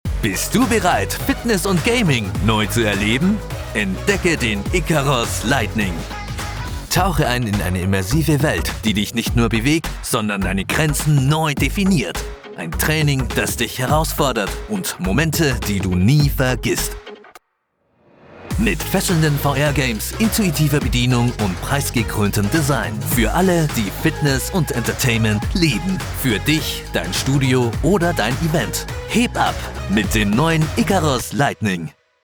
Diep, Stoer, Commercieel, Natuurlijk, Warm
Commercieel